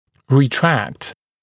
[rɪ’trækt][ри’трэкт]ретрагировать, смещать кзади